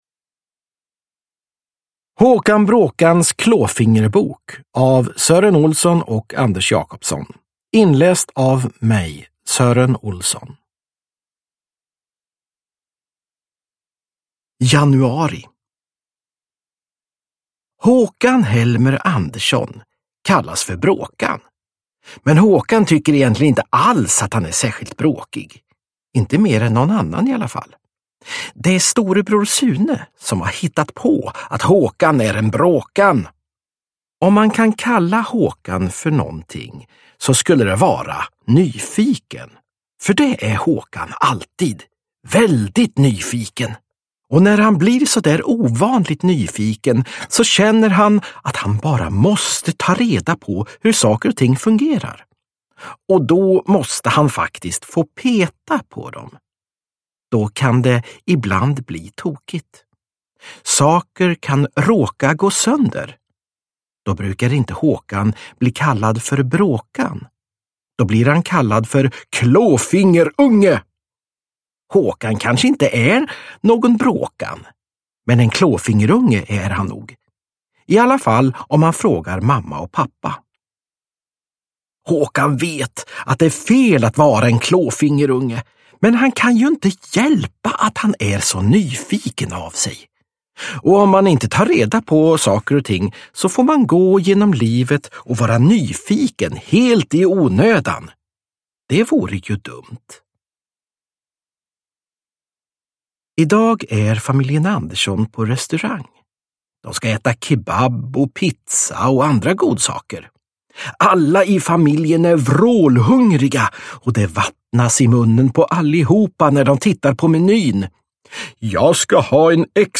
Håkan Bråkans klåfingerbok – Ljudbok
Uppläsare: Sören Olsson